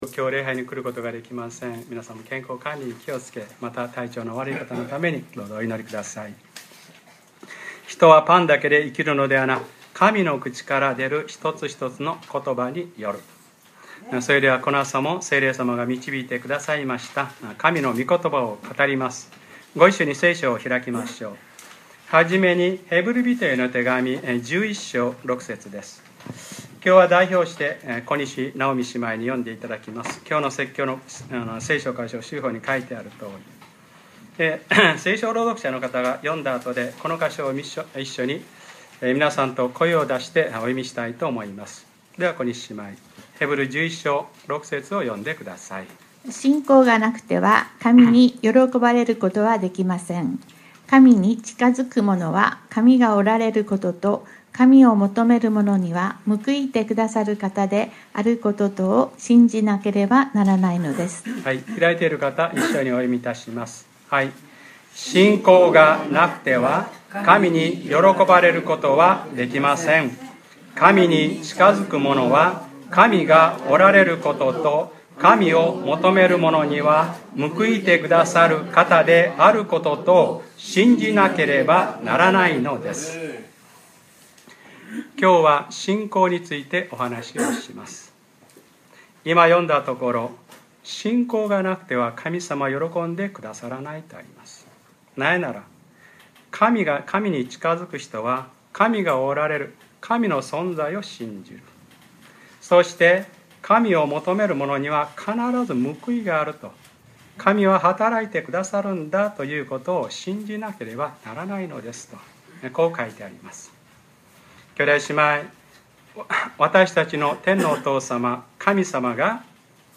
2013年8月4日(日）礼拝説教 『天の御国はパン種のようなものです』